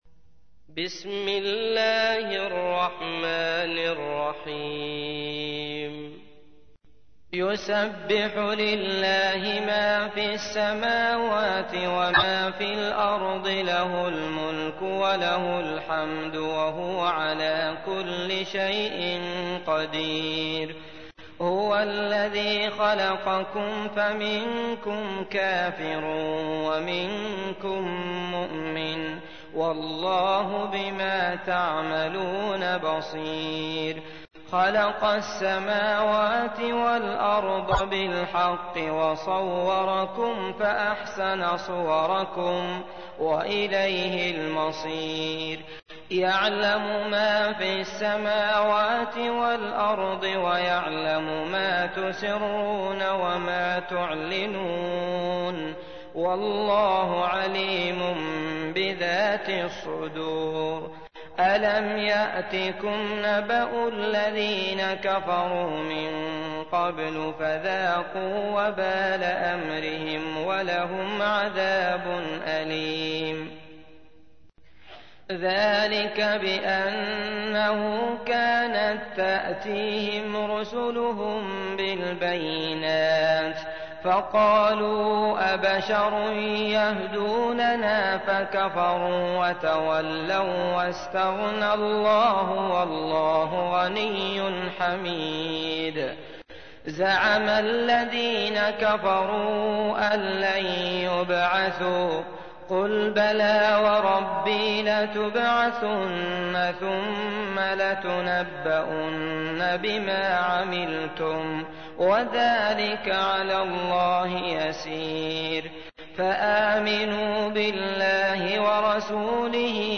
تحميل : 64. سورة التغابن / القارئ عبد الله المطرود / القرآن الكريم / موقع يا حسين